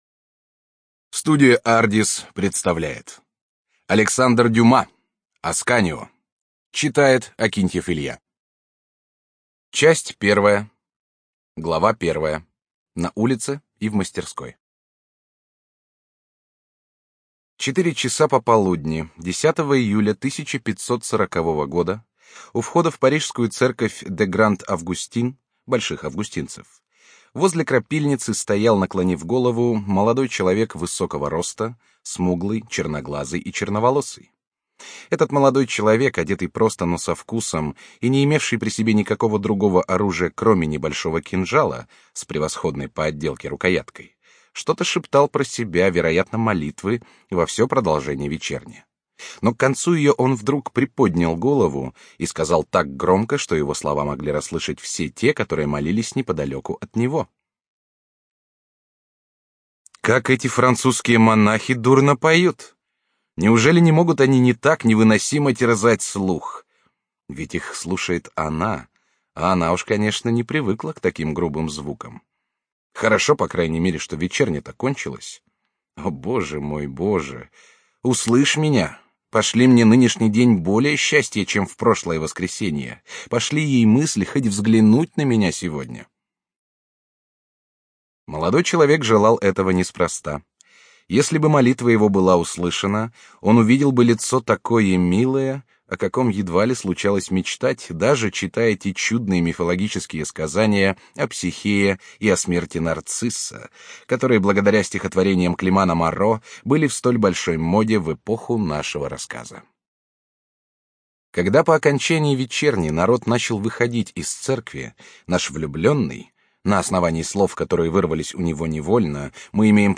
ЖанрКлассическая проза
Студия звукозаписиАрдис